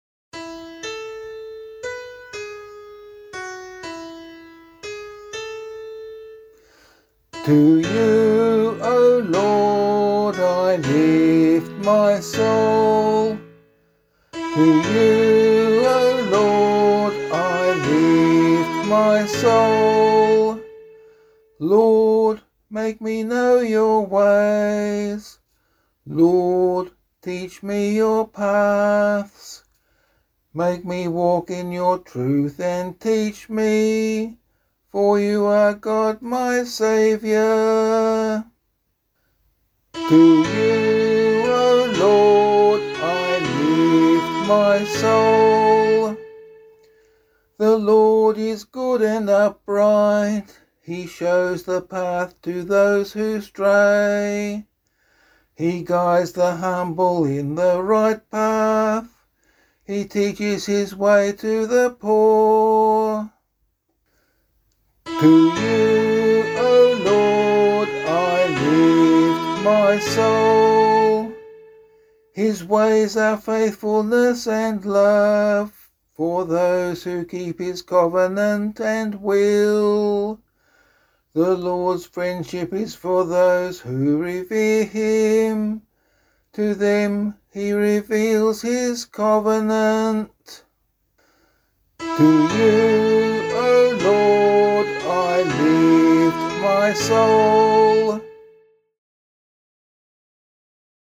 001 Advent 1 Psalm C [LiturgyShare 8 - Oz] - vocal.mp3